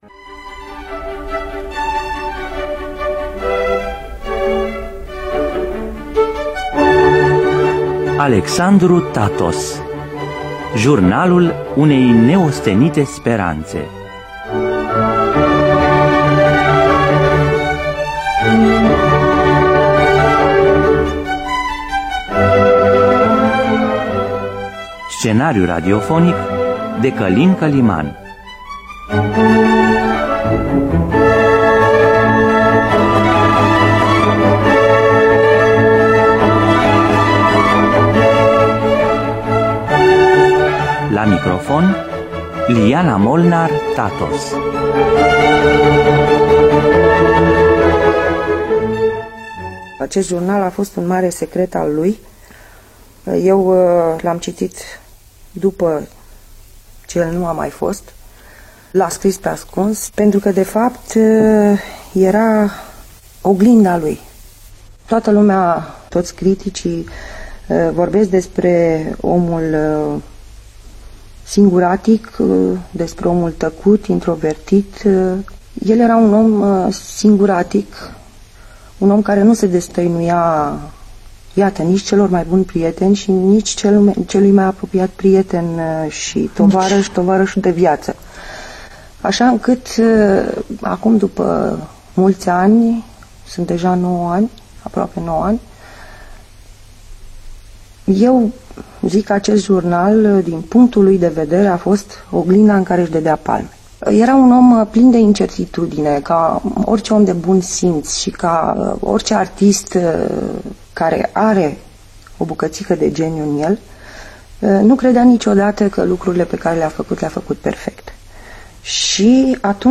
Regia artistică: Mircea Albulescu. În distribuţie: Mircea Albulescu, Mihai Dinvale.